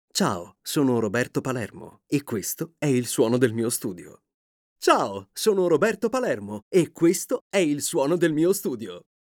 Male
Confident, Corporate, Energetic, Engaging, Friendly, Funny, Sarcastic, Streetwise, Upbeat, Versatile, Warm
SPOT TV-RADIO COMMERCIALS.mp3
Microphone: Neumann TLM-103